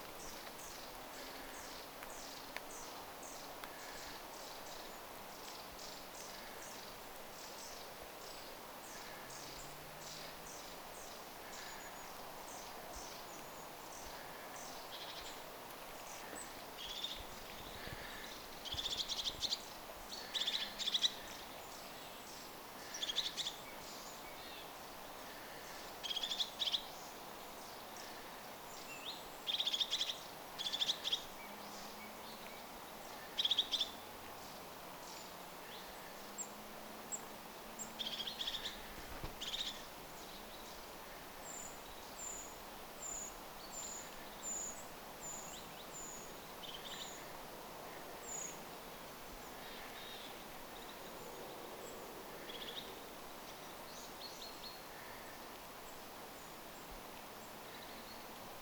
pyrstötiaisparven, tiaisparven ääntelyä,
puukiipijän sirityssarja
pyrstotiais_tiaisparven_aantelya.mp3